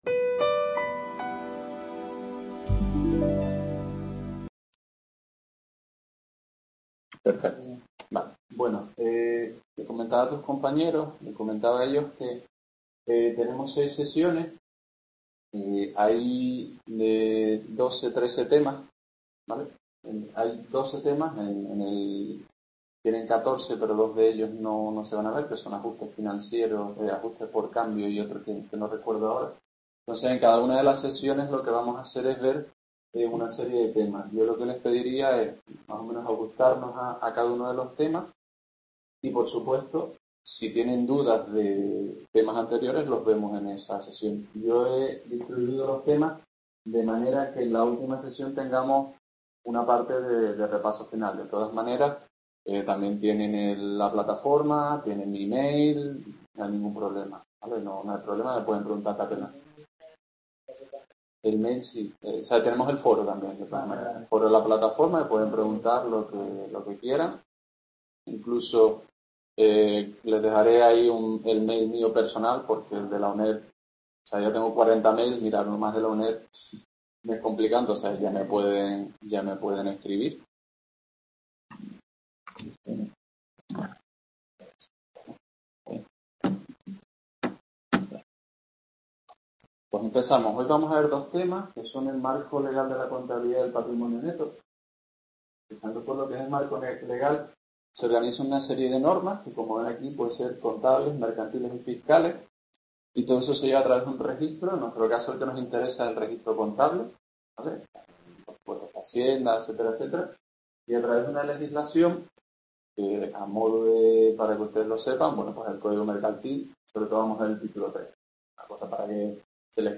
Clase de Contabilidad Financiera 16 de Febrero | Repositorio Digital